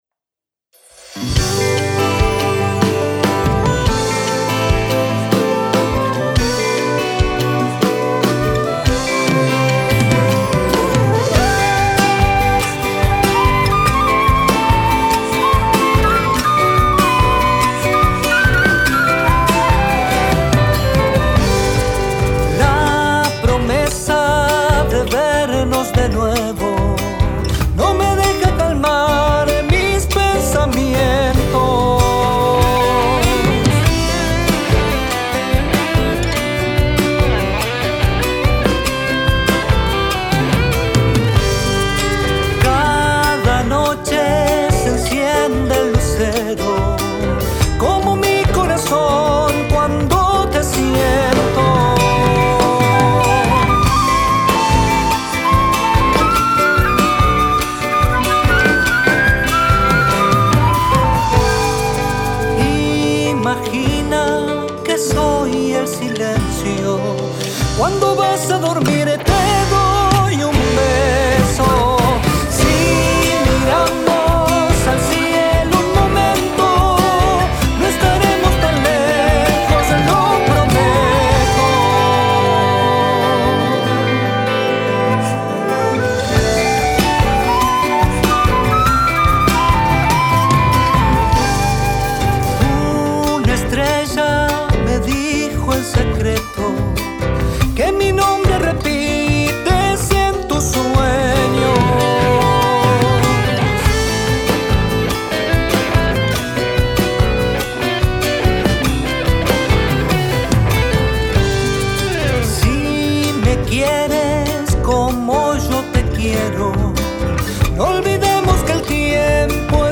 Guitarras Nylon